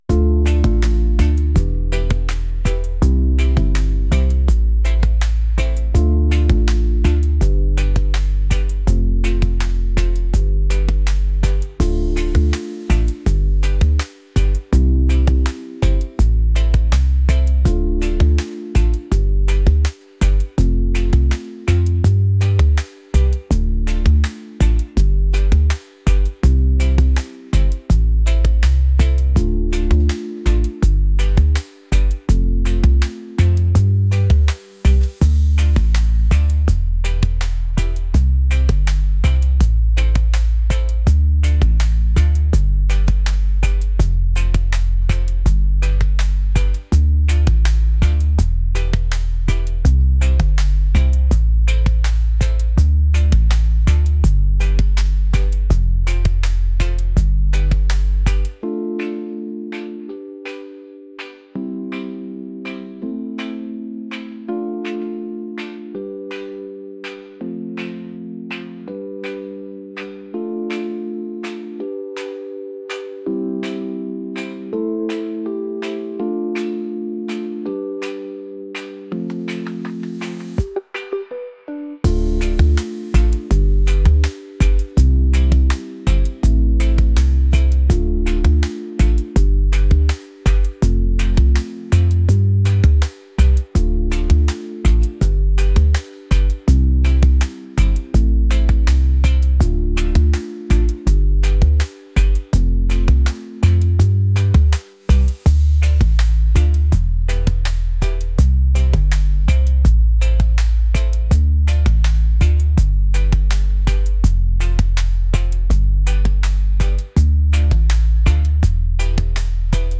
reggae | laid-back